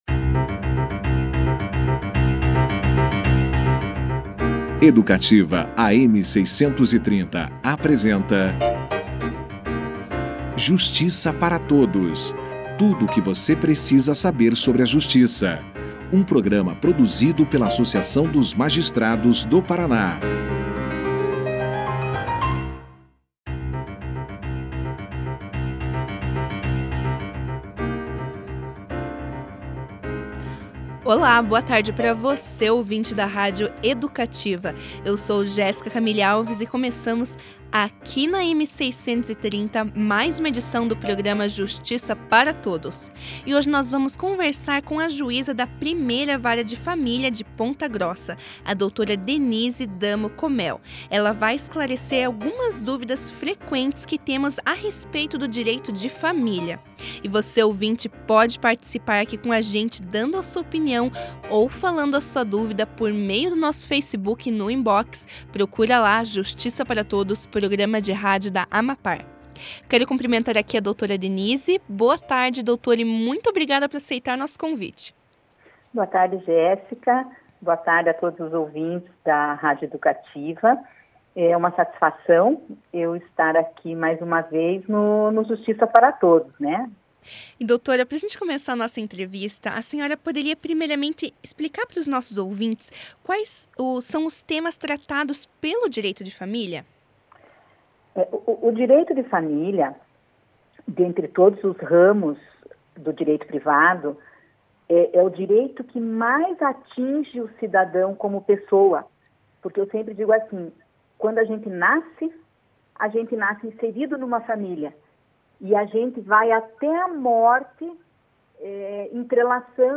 No programa Justiça para Todos desta quarta-feira (14), a juíza da 1ª Vara de Família de Ponta Grossa, Denise Damo Comel, esclareceu algumas dúvidas frequentes a respeito do Direito de Família. Já no início da entrevista, a magistrada explicou aos ouvintes da rádio Educativa, AM 630, quais são os temas tratados pelo Direito de Família.
Durante a entrevista, a magistrada falou sobre guarda compartilhada, abandono afetivo, entre outros assuntos.